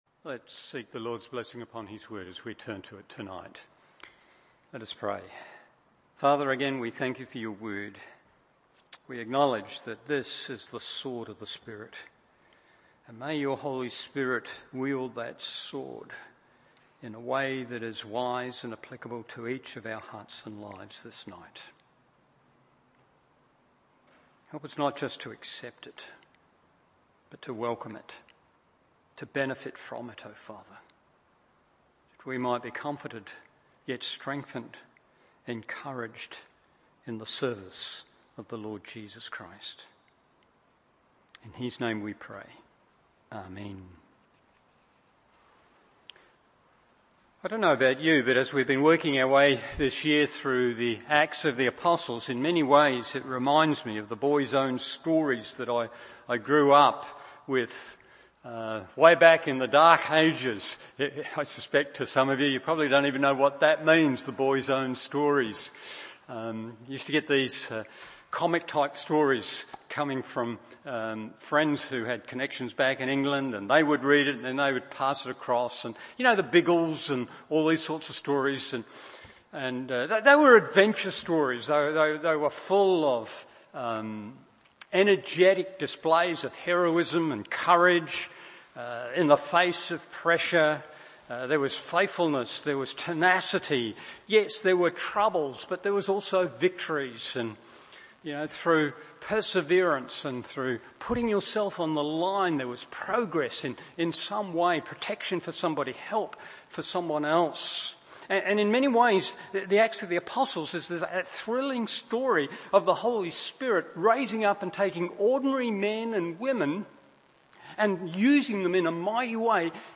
Evening Service Acts 22:22-23:10 1.